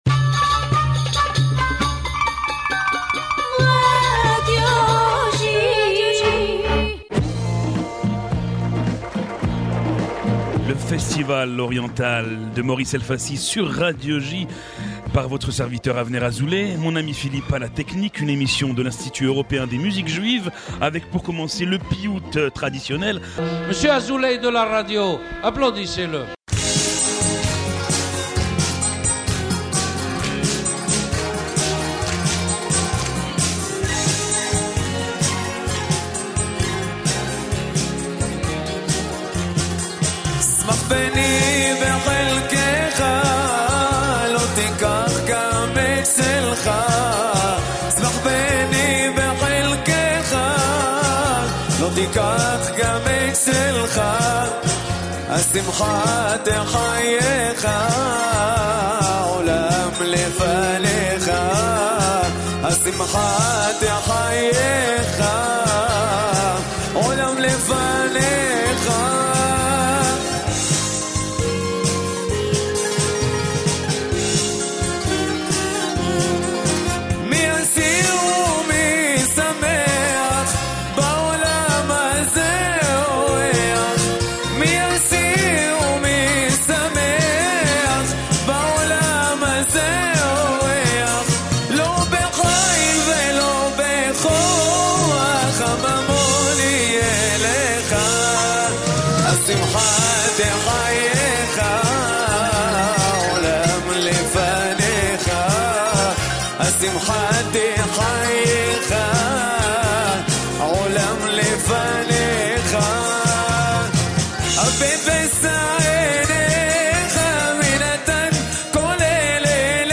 « Le festival oriental » est une émission de l’Institut Européen des Musiques Juives entièrement dédiée à la musique orientale.